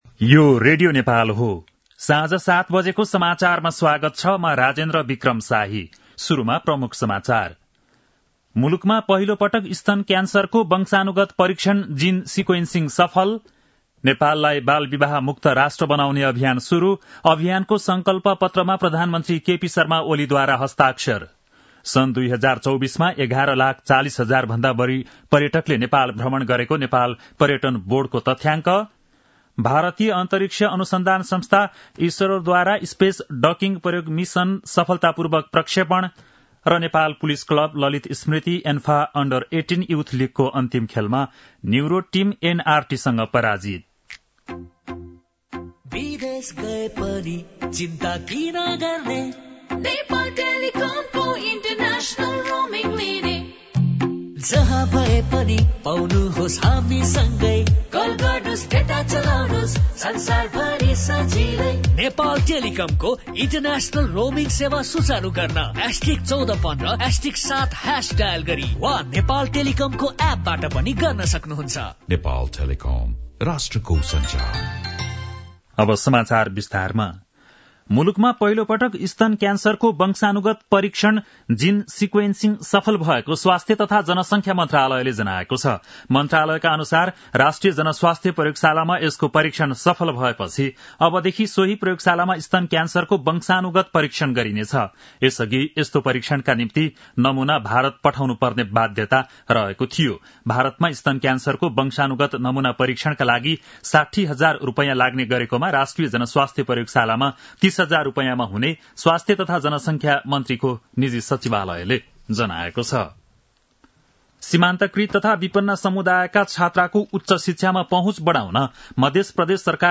बेलुकी ७ बजेको नेपाली समाचार : १७ पुष , २०८१